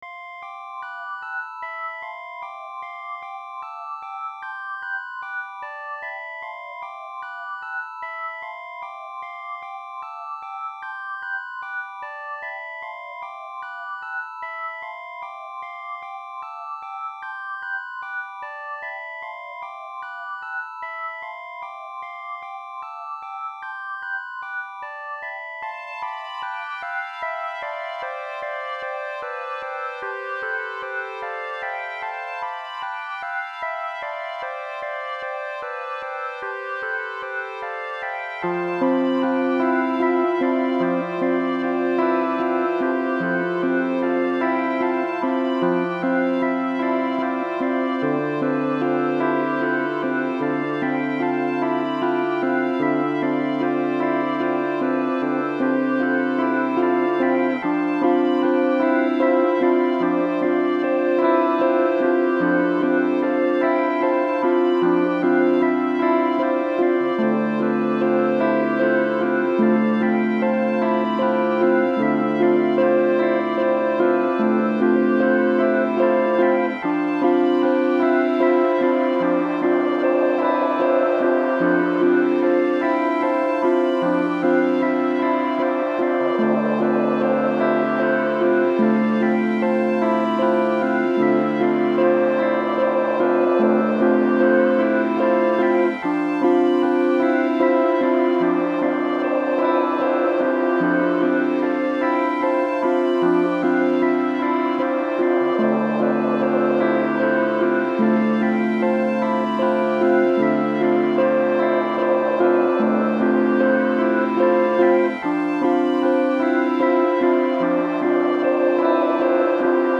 a soothing melody made of guitar plucks and keyboard lines.